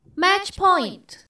ui_scoremgs_matchpoint.wav